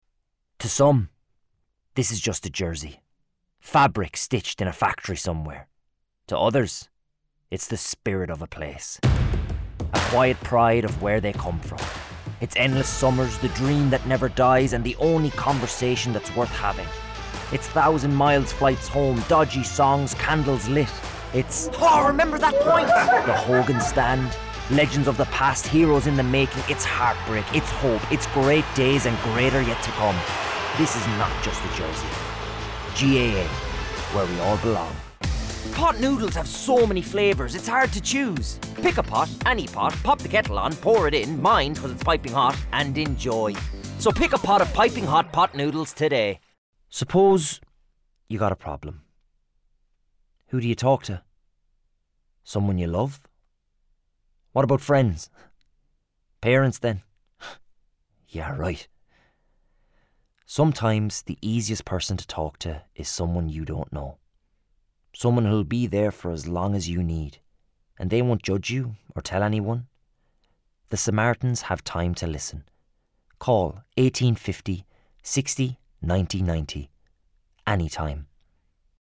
Male
20s/30s, 30s/40s
Irish Midlands, Irish Neutral